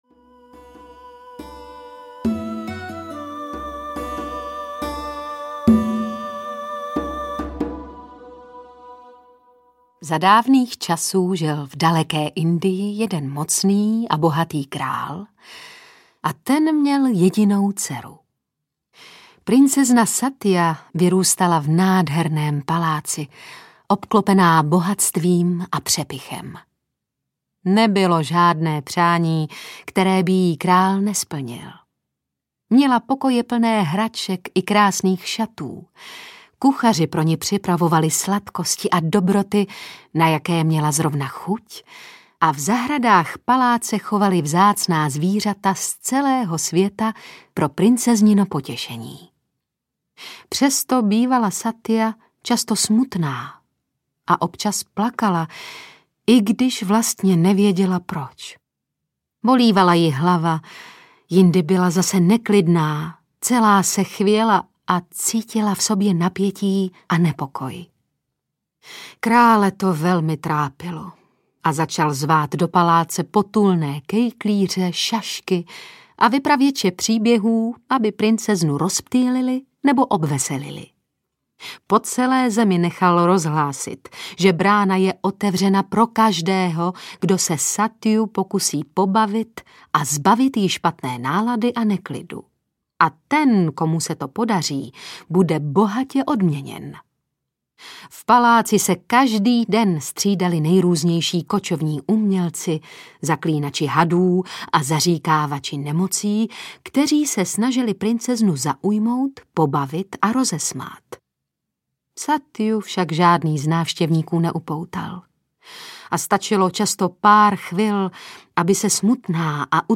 Ukázka z knihy
Vyprávění obou tvůrců společně s dalšími texty a staroindickými bajkami načetli herci Tereza Bebarová a David Matásek. Nejen malým a velkým příznivcům jógy, ale i všem posluchačům se zájmem o relaxaci, duchovní učení, alternativní životní styl a zajímavé myšlenky východních filozofií je určeno 45 povídání a cvičení doprovázených instrumentální hudbou.